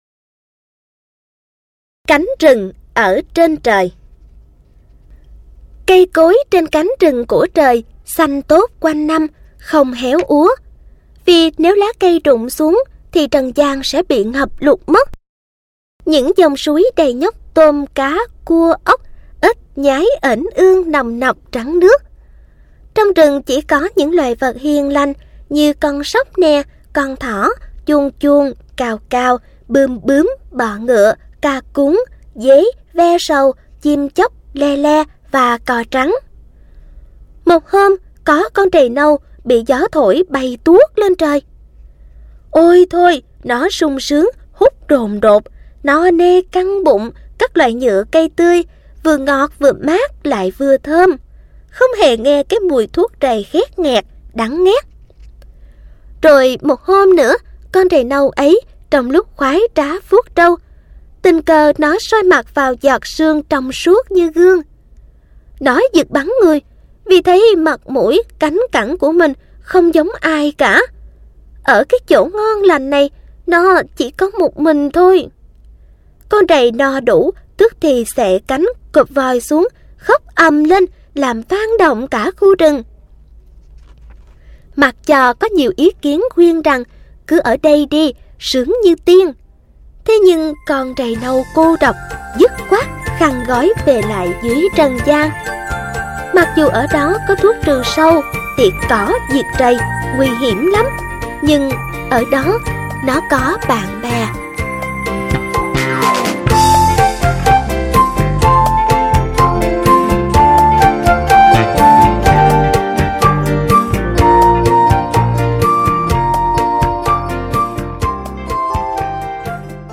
Sách nói | Xóm Đồ Chơi P4